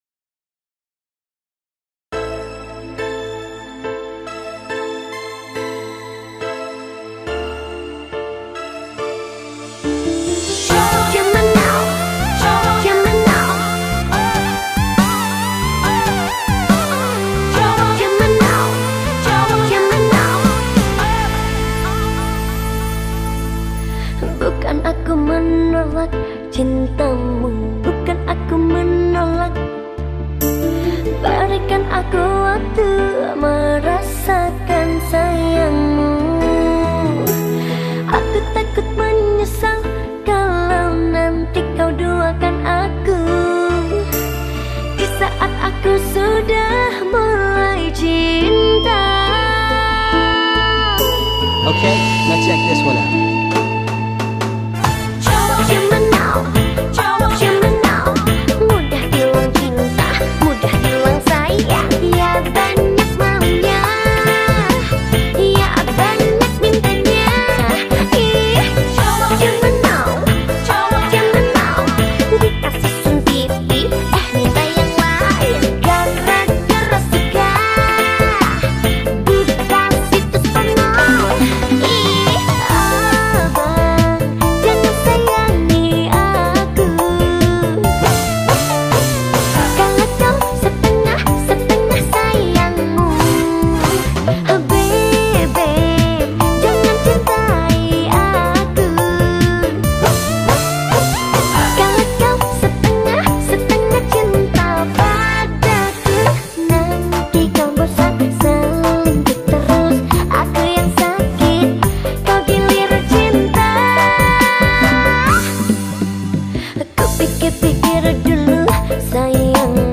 Lagu Dangdut